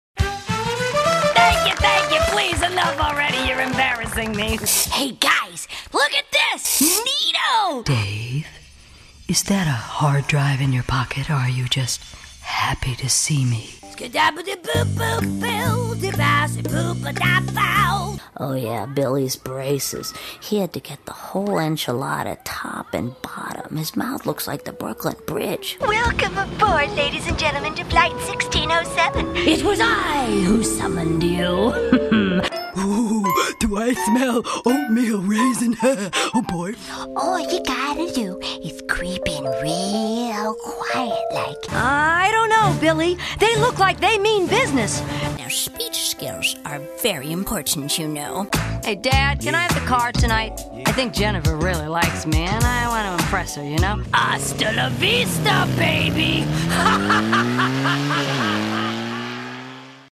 I'm a very experienced & versatile voice actor with a pro-level home studio.
Character Demo